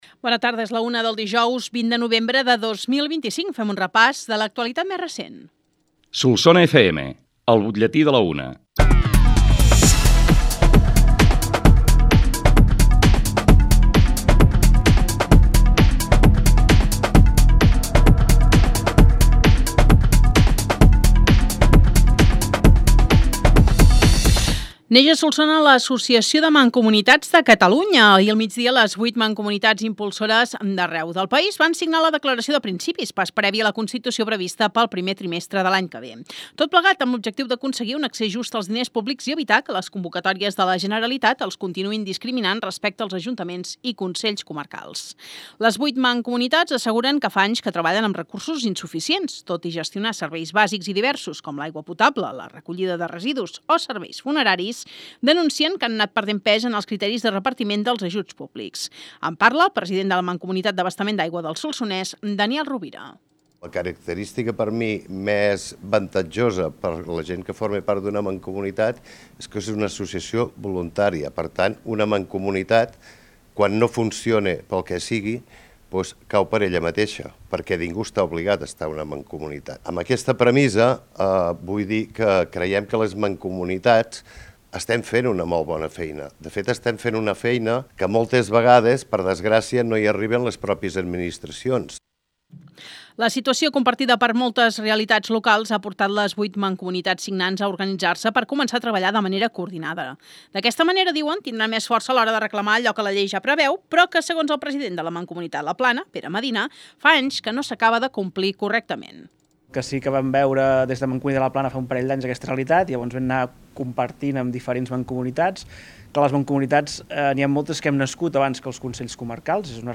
L’ÚLTIM BUTLLETÍ
BUTLLETI-20-NOV-25.mp3